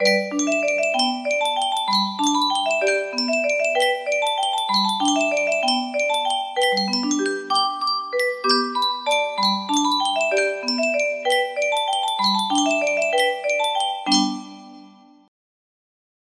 Yunsheng Music Box - Arirang Y300 music box melody
Full range 60